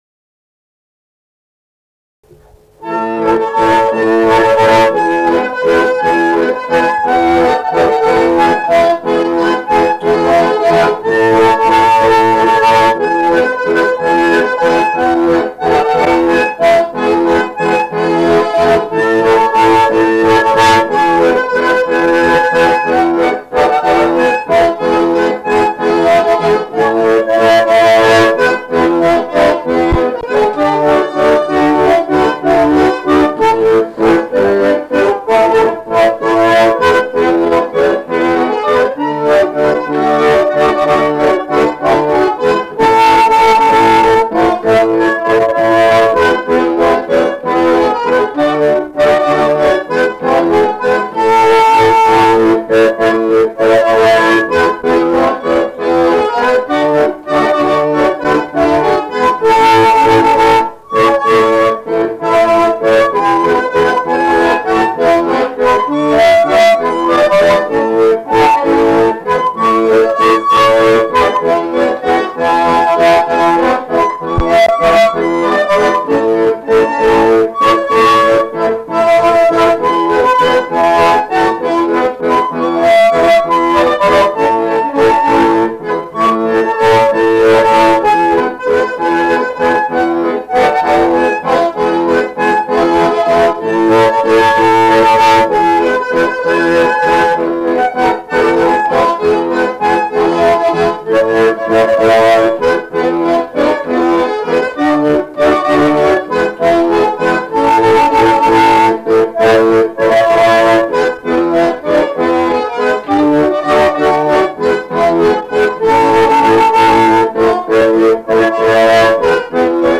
Valsas
šokis
Ryžiškė
instrumentinis
armonika